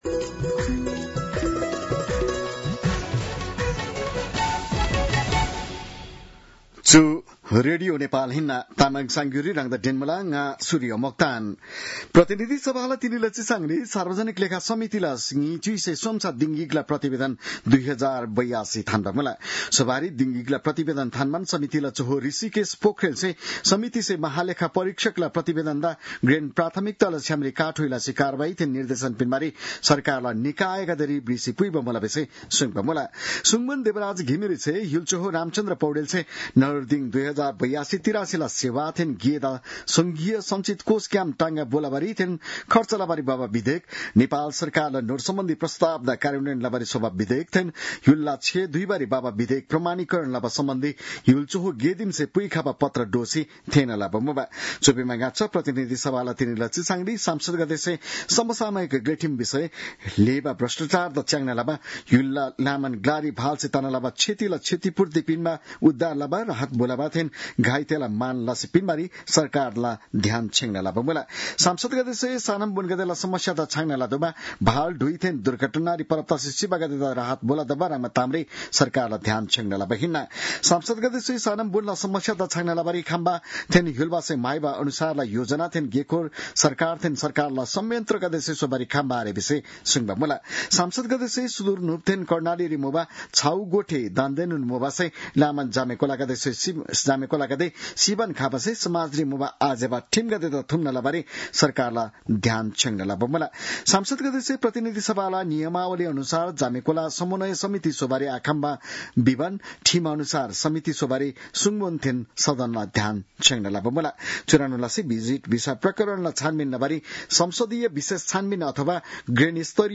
तामाङ भाषाको समाचार : ३१ असार , २०८२